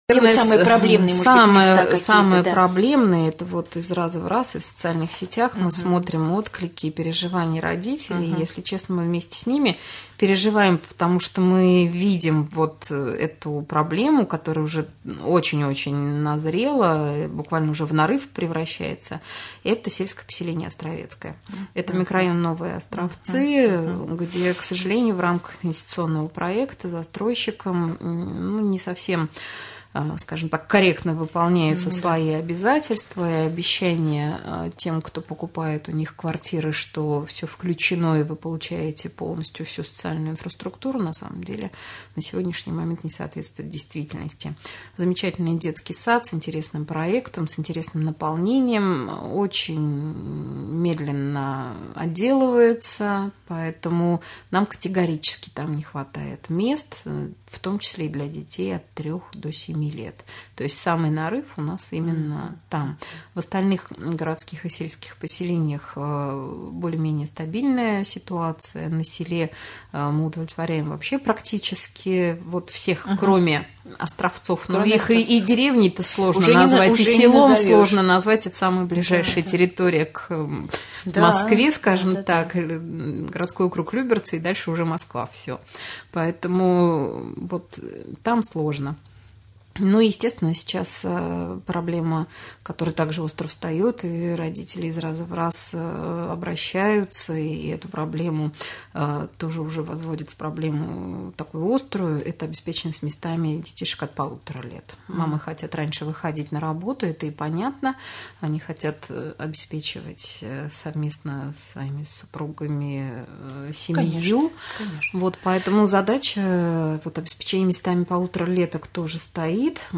Учитывается ли мнение родителей в комитете по образованию? Как реагируют сотрудники комитета по образованию на проблему в с.п. Островецкое? Об этом и многом другом рассказала Наталья Асеева, председатель комитета по образованию Раменского района в прямом эфире Раменского радио. Интервью